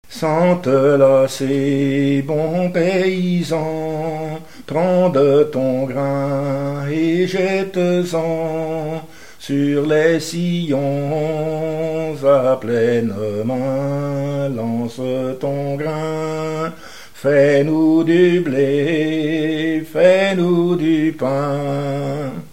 chanteur(s), chant, chanson, chansonnette
Pièce musicale inédite